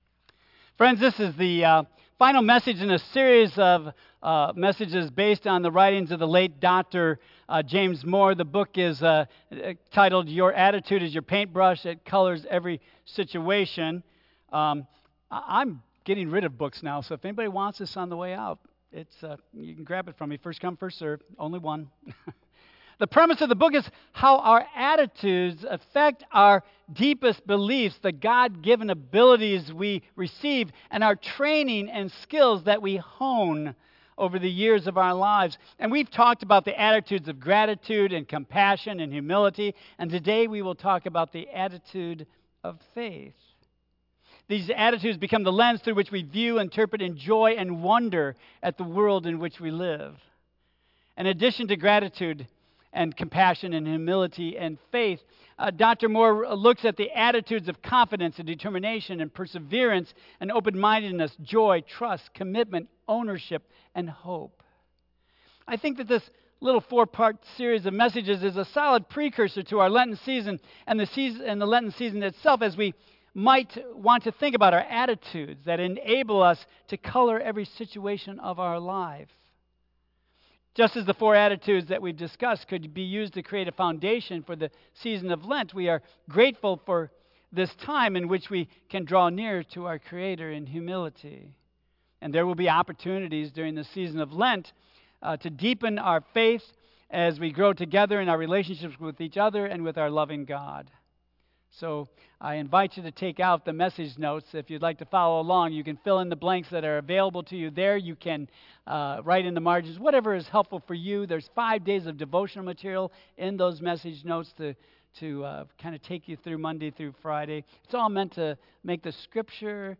Tagged with Michigan , Sermon , Waterford Central United Methodist Church , Worship Audio (MP3) 9 MB Previous The Attitude of Humility Next Love Versus Revenge